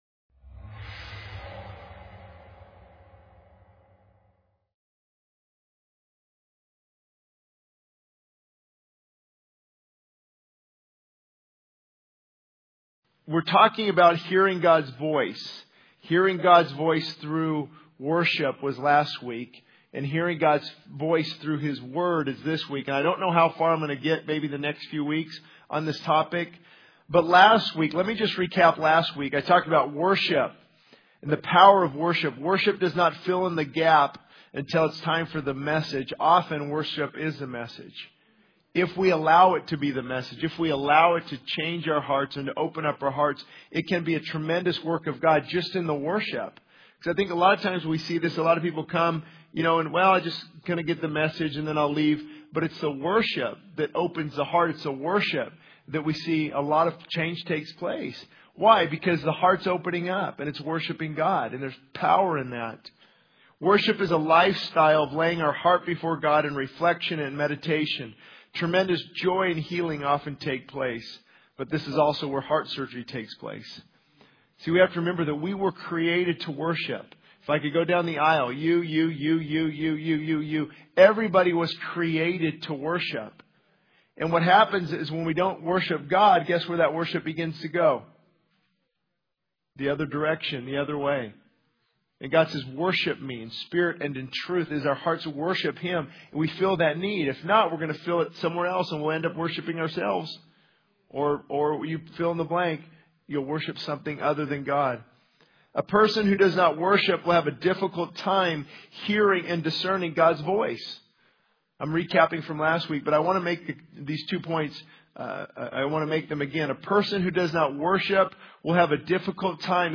This sermon emphasizes the importance of worshiping God to hear His voice clearly. It addresses the hindrances to worship, such as lack of genuine relationship with Christ, holding onto besetting sins, pride, and wrong perceptions of worship. The speaker challenges the audience to move from conviction to action, highlighting the need for true repentance and returning to God in worship to experience His grace and peace.